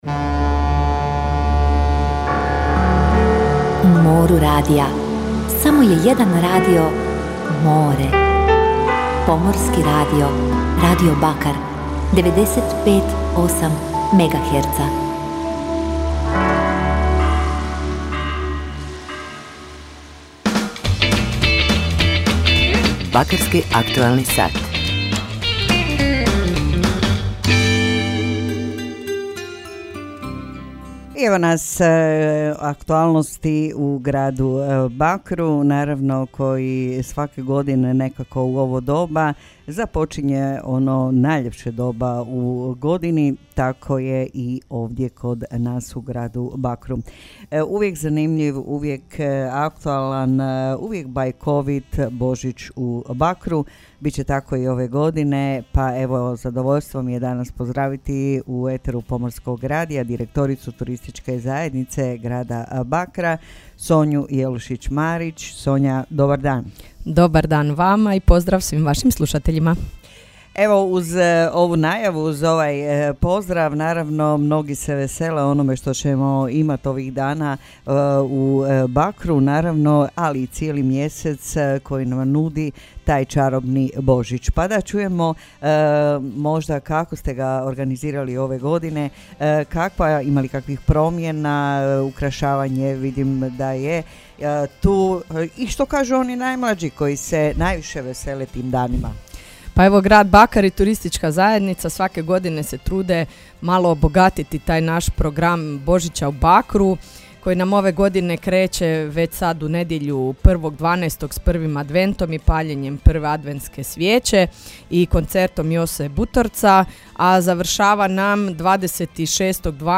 [ADVENTI]; Božić u Bakru! Bogat program! [INTERVJU];